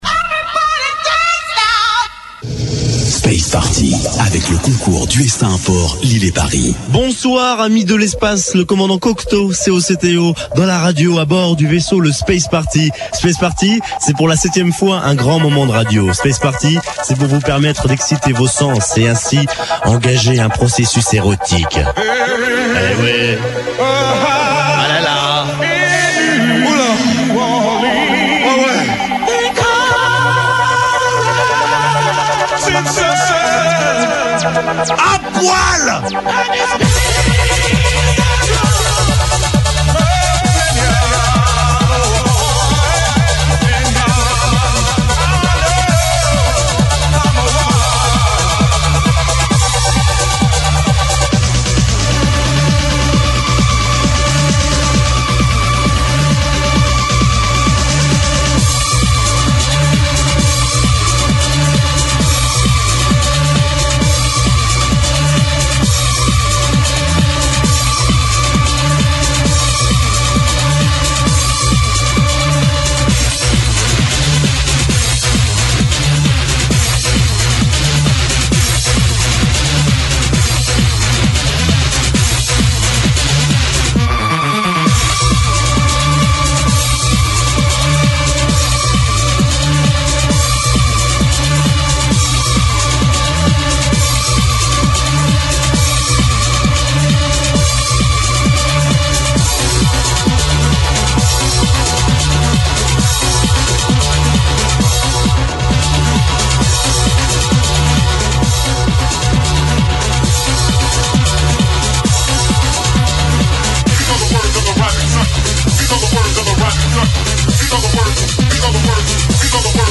Un mix 100% generation 90 !